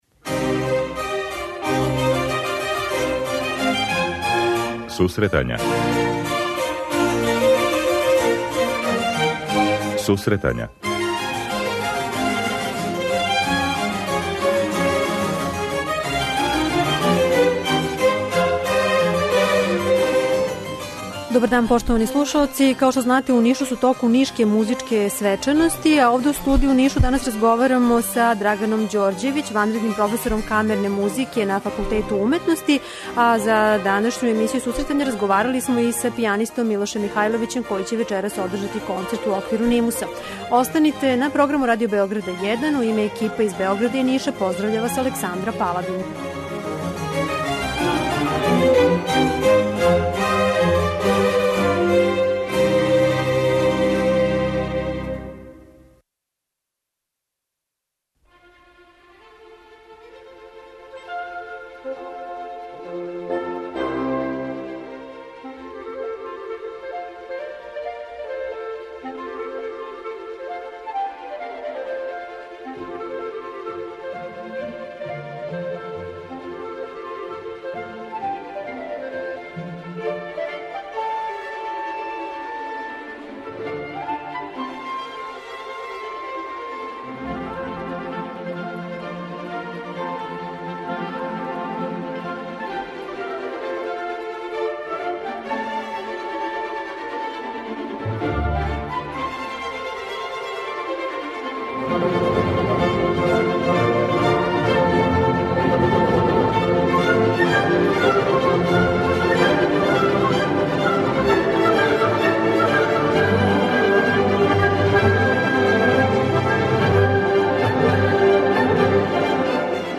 И данашња емисија се реализује директно из Ниша.